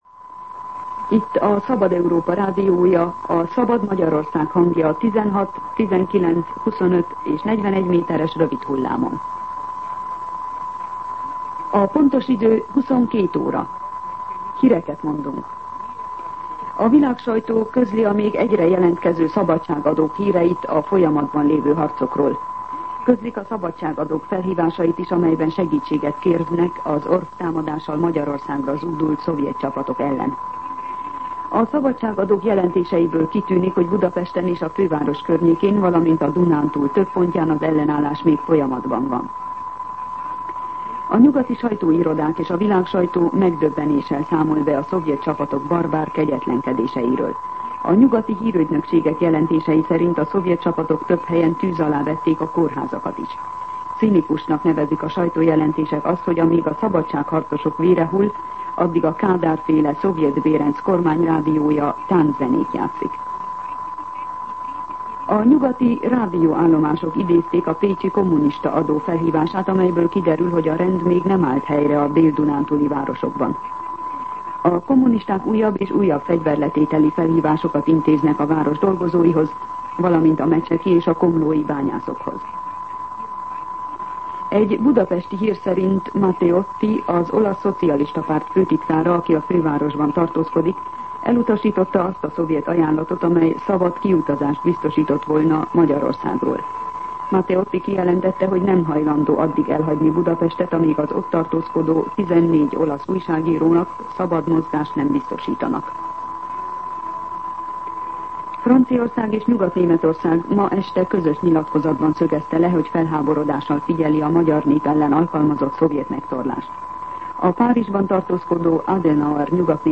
22:00 óra. Hírszolgálat